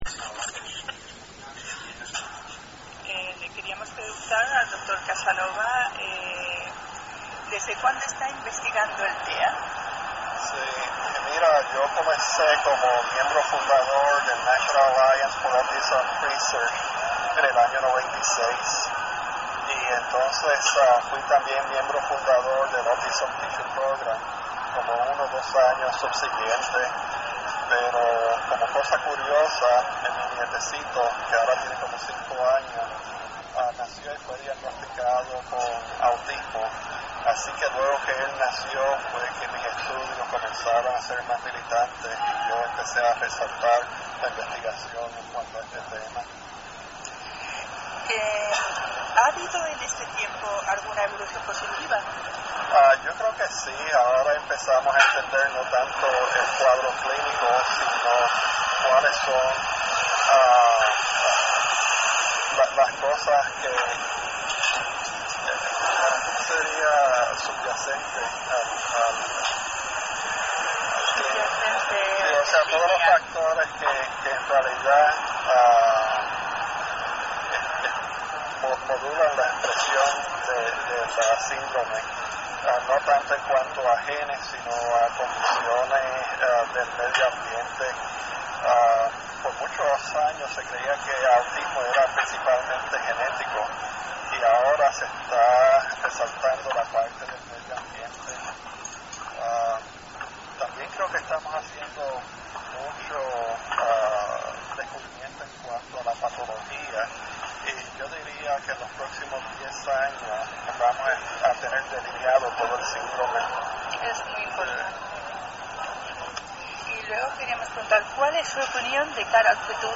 entrevista.mp3